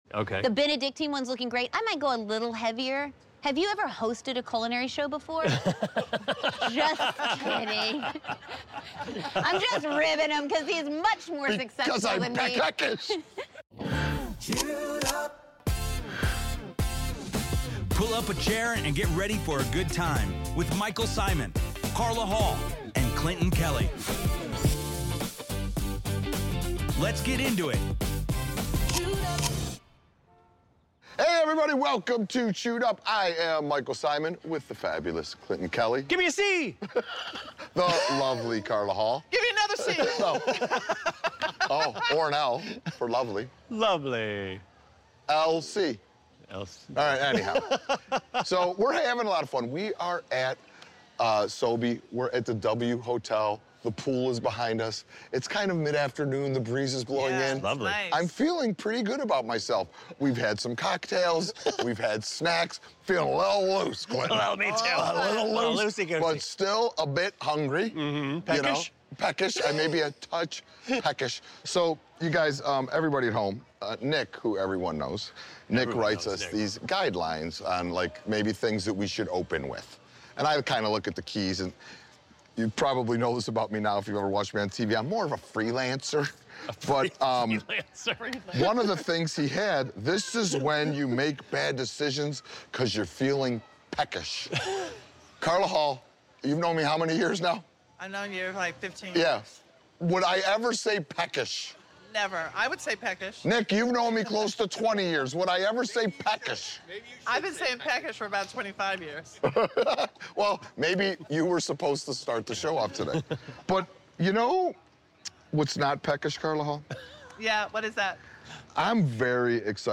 In this lively, sun-soaked episode of Chewed Up, Michael Symon, Carla Hall, and Clinton Kelly welcome the hilarious and wildly creative Damaris Phillips to the kitchen. Set poolside at the W Hotel during SOBE, the group dives into a playful, Southern-inspired take on classic tea sandwiches.